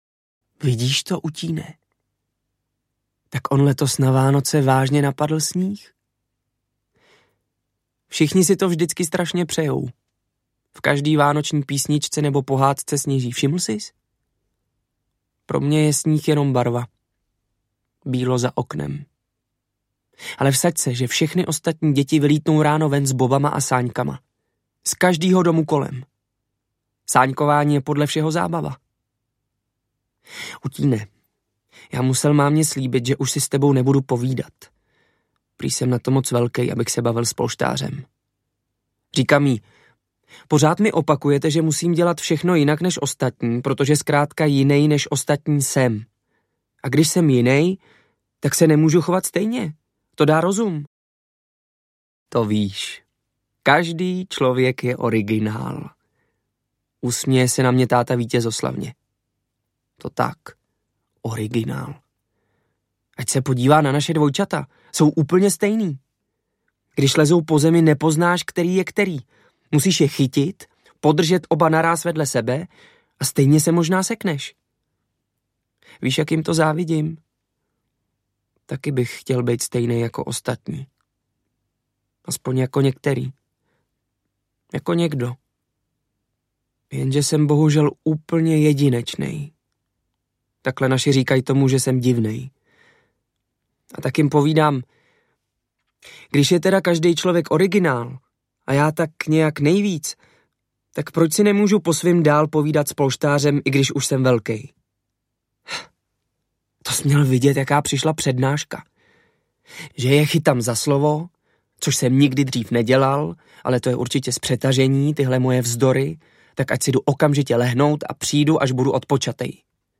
Audio knihaTonda, Slávka a kouzelné světlo
Ukázka z knihy